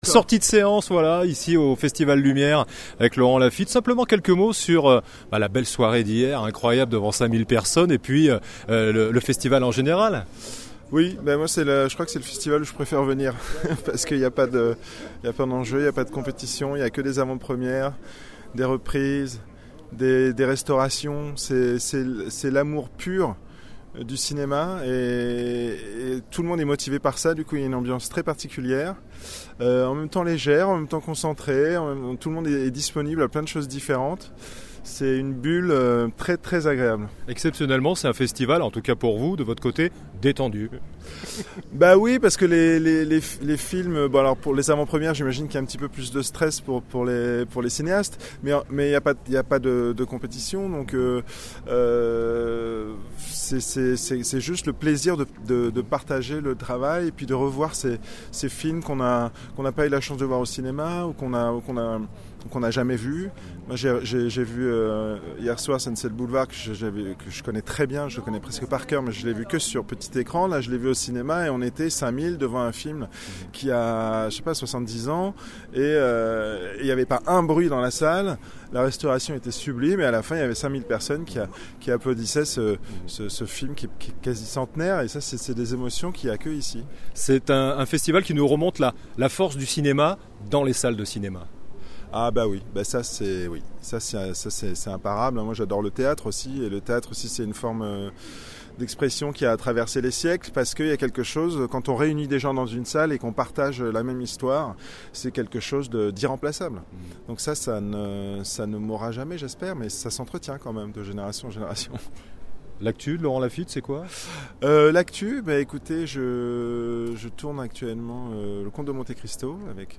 Laurent Lafitte etait l'un des invités d'honneur du festival Lumière 2023. La radio du cinéma l'a rencontré en sortie de projection.